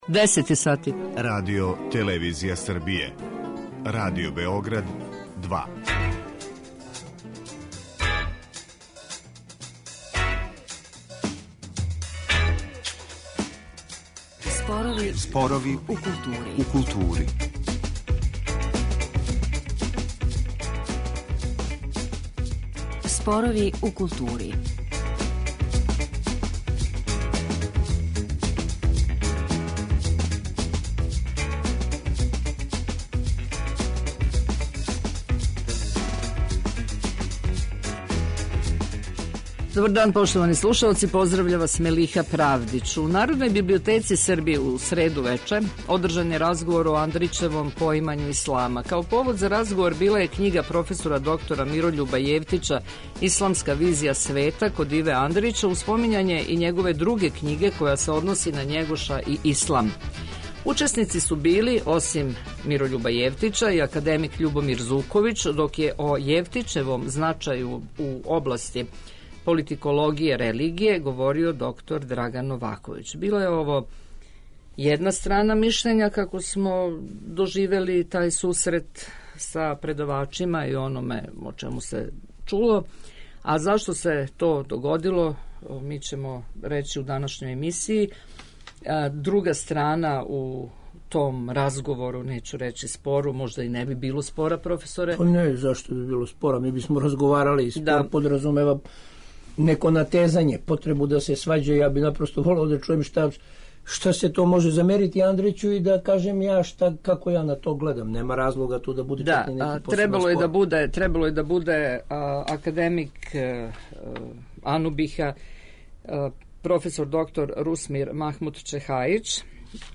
Колико су оваква тумачења у сврси разумевања Андрићевог дела, а колико из ванкњижевних ралога, ако их има могу и да нашкоде, уводна је тема за данашњу дебату.